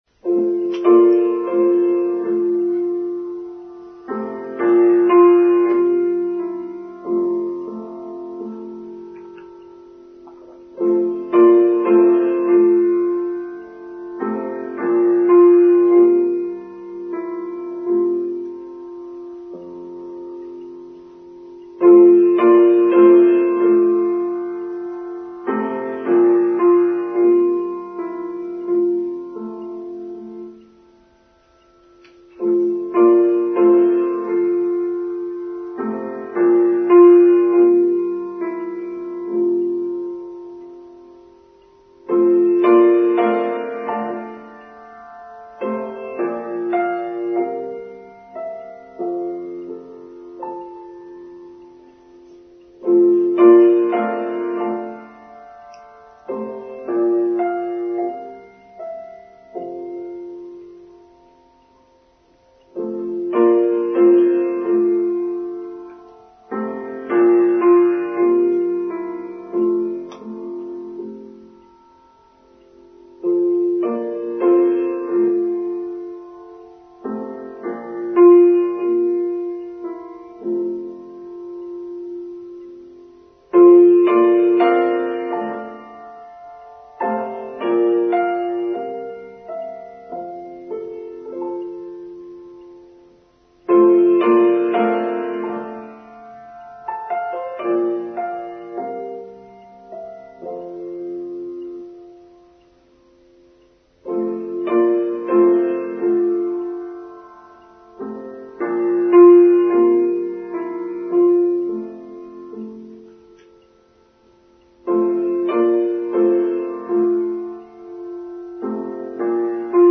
Paths to Inner Peace: Online Service for Sunday 15th October 2023